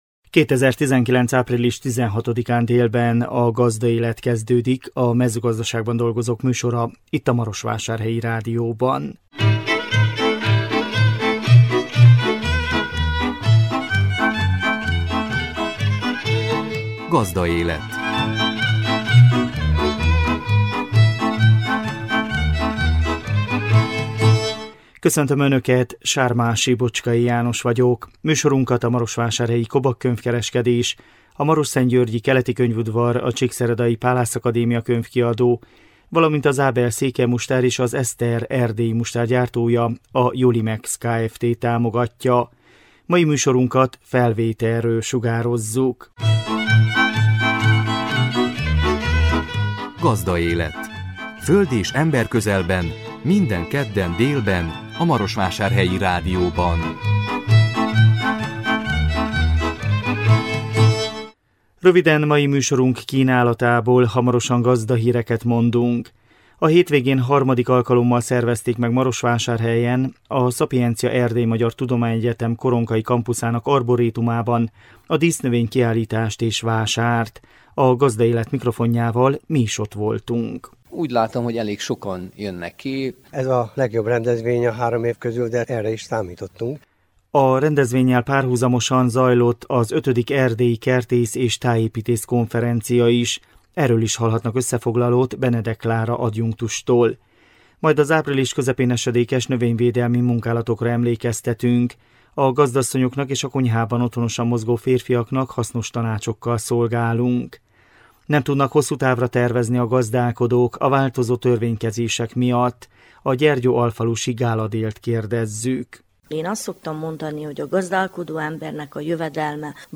A 2019 április 16-án jelentkező műsor tartalma: Gazdahírek, A hétvégén III. alkalommal szervezték meg Marosvásárhelyen, a Sapientia EMTE koronkai kampuszának arbórétumában a dísznövény kiállítást és vásárt. A Gazdaélet mikrofonjával mi is ott voltunk.